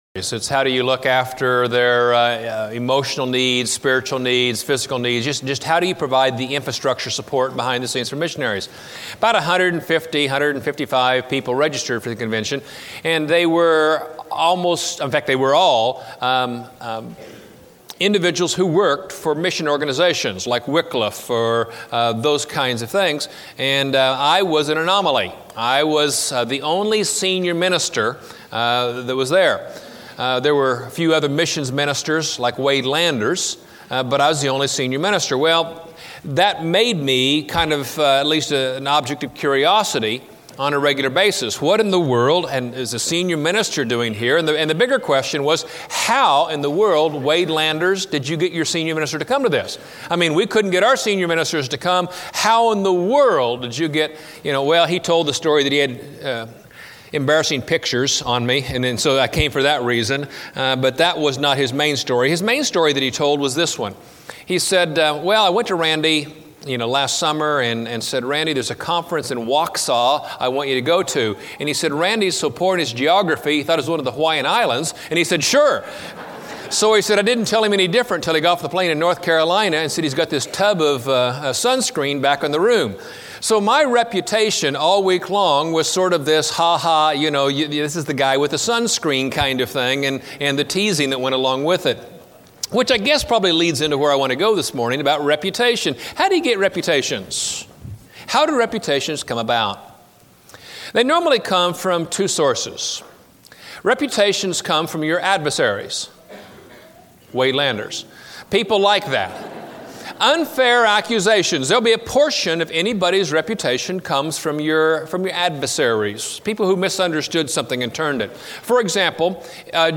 Why I Believe in the Resurrection Preached at College Heights Christian Church November 20, 2005 Series: 1 Corinthians 2005 Scripture: 1 Corinthians 15-16 Audio